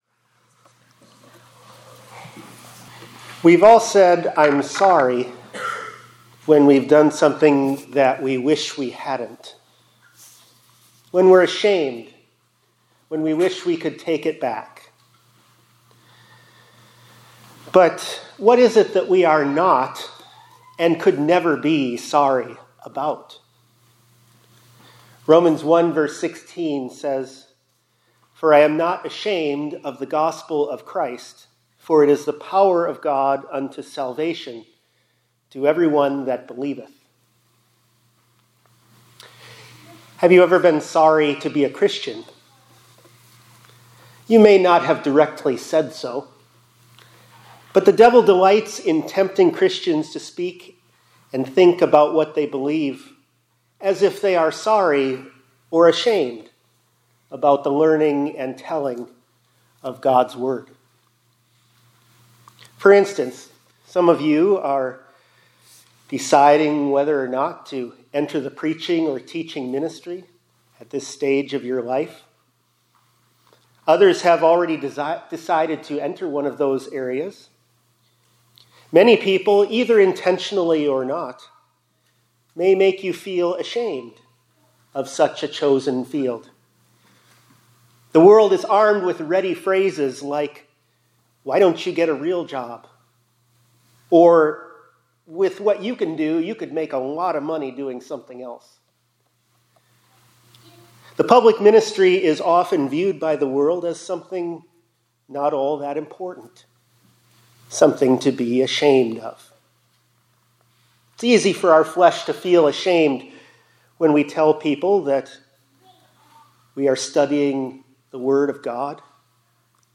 2025 Chapels -